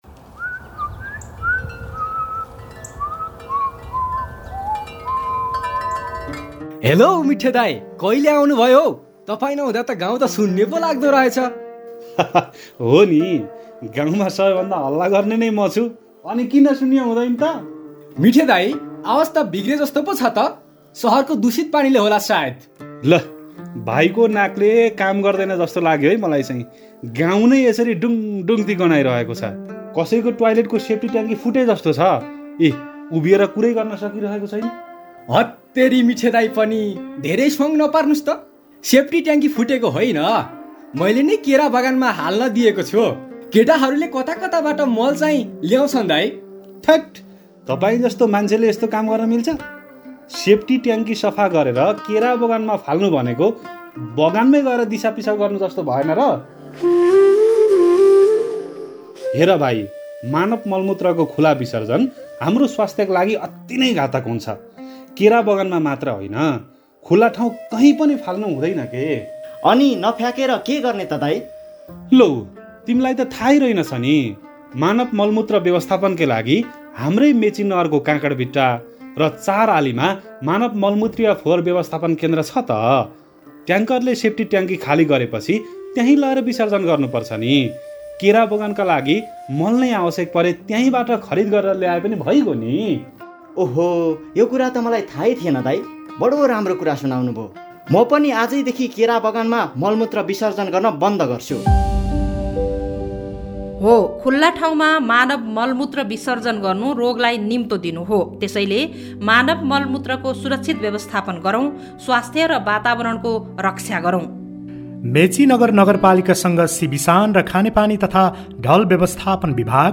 काठमाडौः नगरव्यापी समावेशी सरसफाइ सम्बन्धी जनहितकारी रेडियो सन्देशहरु उत्पादन गरिएको छ ।